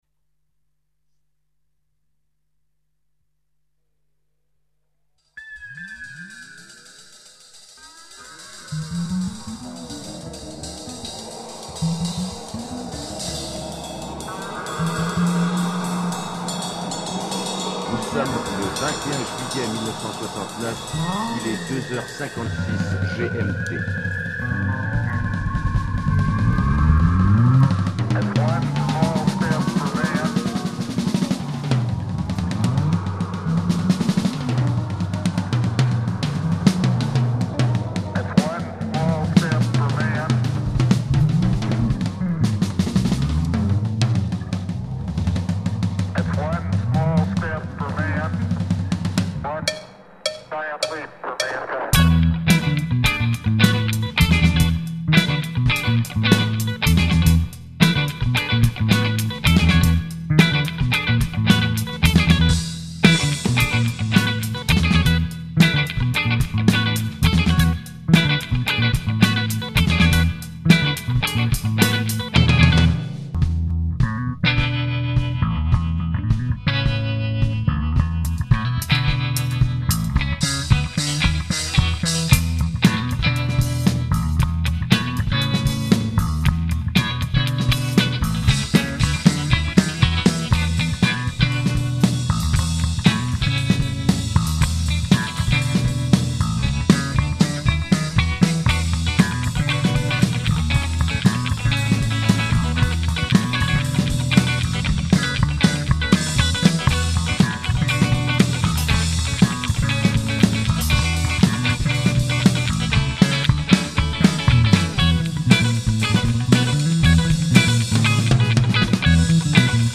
Titles on the left are songs interpreted by the whole band.